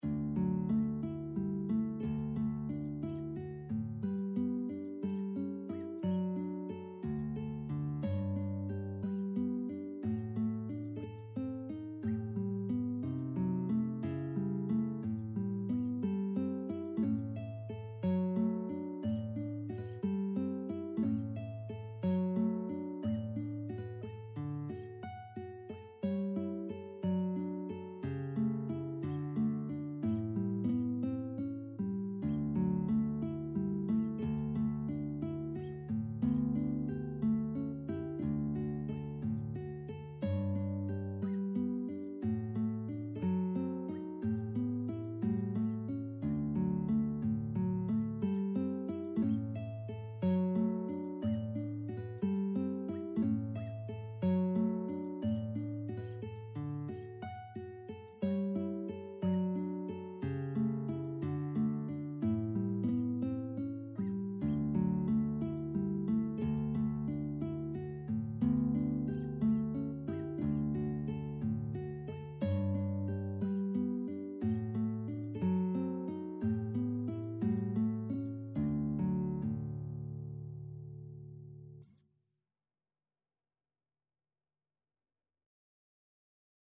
Guitar version
~ = 60 Andantino (View more music marked Andantino)
2/4 (View more 2/4 Music)
Guitar  (View more Intermediate Guitar Music)
Classical (View more Classical Guitar Music)